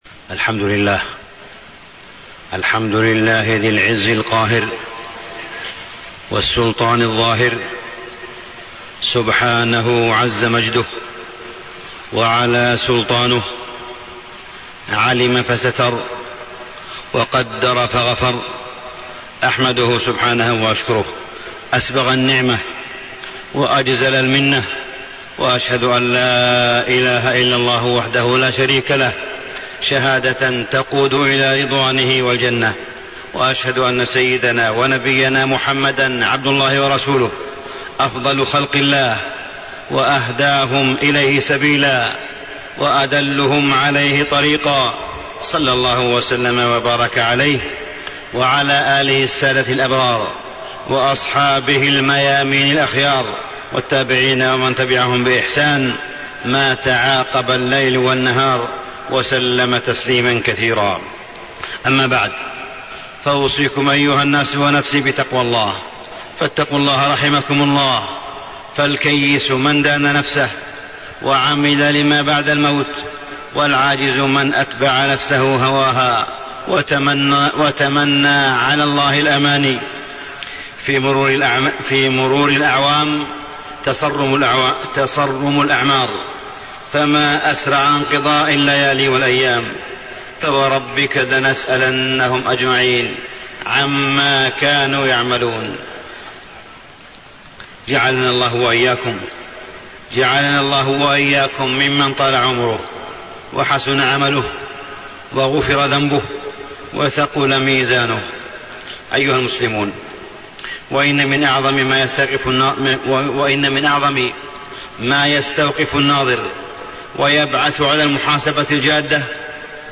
تاريخ النشر ١٦ محرم ١٤٣٤ هـ المكان: المسجد الحرام الشيخ: معالي الشيخ أ.د. صالح بن عبدالله بن حميد معالي الشيخ أ.د. صالح بن عبدالله بن حميد الشائعات وأثرها السيئ على الأمة